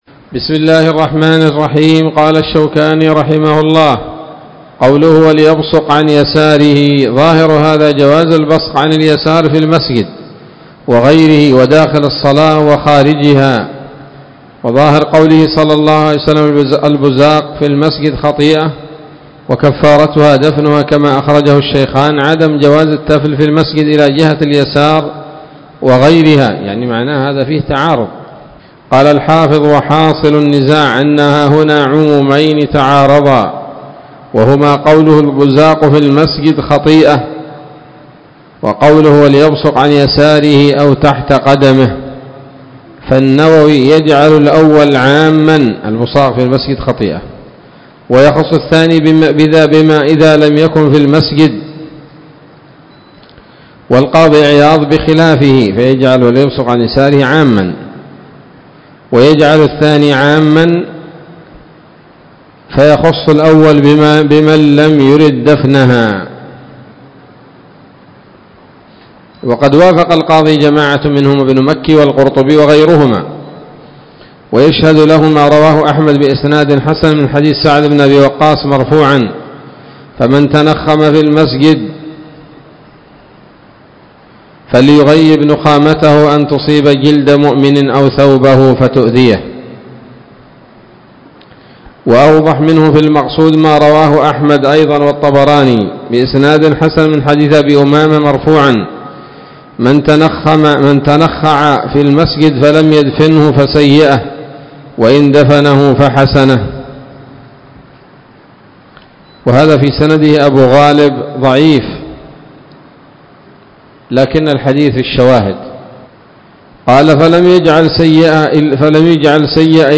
الدرس الثامن عشر من أبواب ما يبطل الصلاة وما يكره ويباح فيها من نيل الأوطار